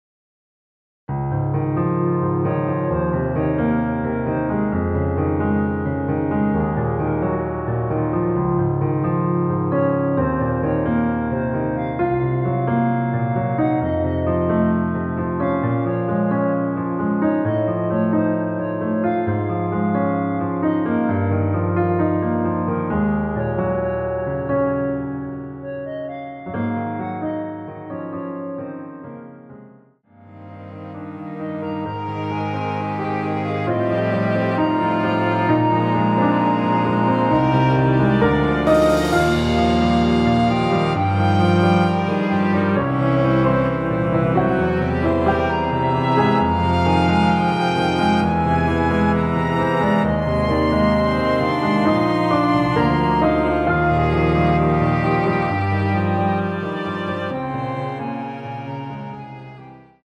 원키에서(-3)내린 멜로디 포함된 MR입니다.(미리듣기 확인)
Db
앞부분30초, 뒷부분30초씩 편집해서 올려 드리고 있습니다.
중간에 음이 끈어지고 다시 나오는 이유는